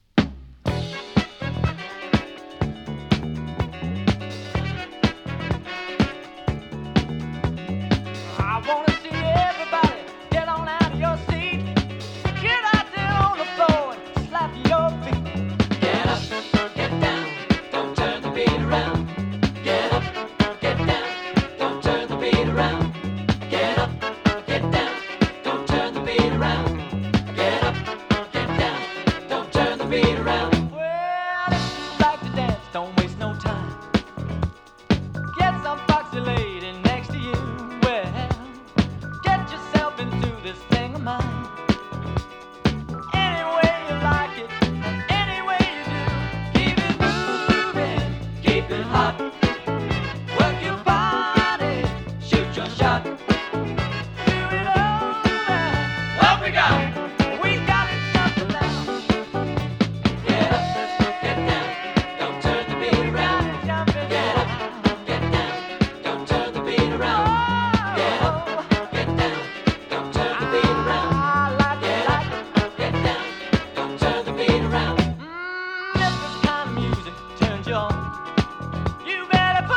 AOR ブリティッシュファンク 王道ソウル フリーソウル